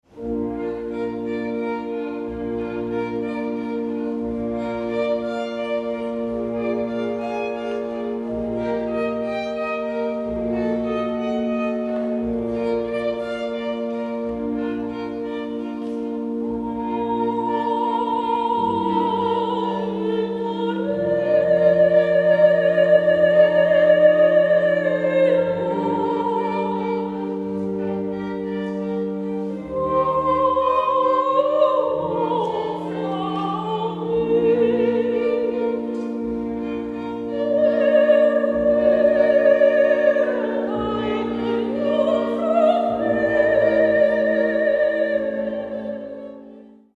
Festliche Streicherklänge zur Zeremonie
(Besetzung D: Streichquartett, SolosängerIn und Orgel)